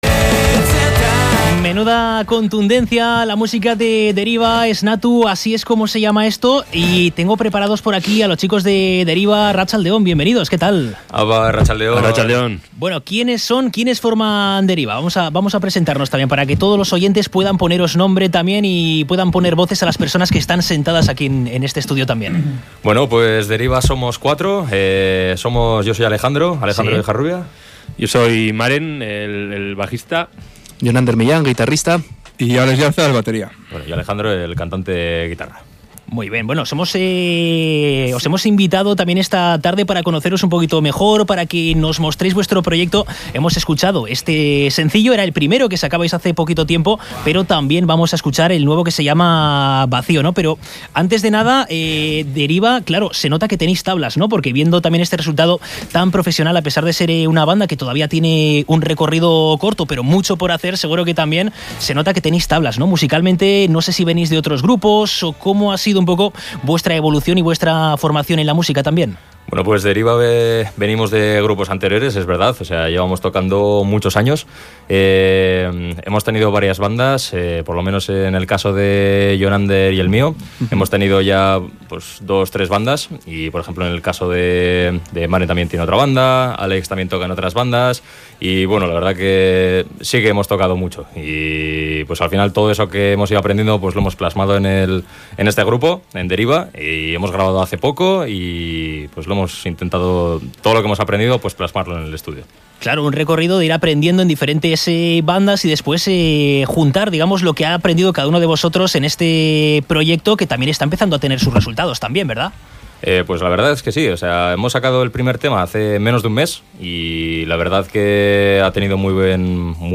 Escucha la entrevista a Deriba en la que charlamos sobre sus primeras canciones, los últimos conciertos y los proyectos de futuro